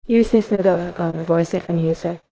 Fake My Voice is a deep learning-powered multi-speaker Text-to-Speech (TTS) system designed to clone voices with high fidelity. By extracting a unique 256-D speaker embedding from just a few seconds of reference audio, the pipeline replicates the specific tone, accent, and style of any target speaker.
• WaveGlow: A flow-based neural vocoder for natural waveform generation.